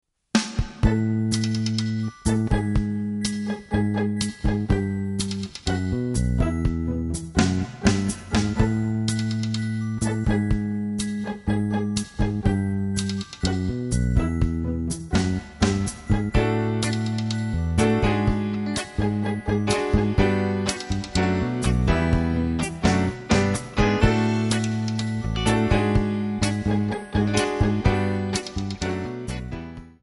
Eb
MPEG 1 Layer 3 (Stereo)
Backing track Karaoke
Pop, Oldies, Duets, 1960s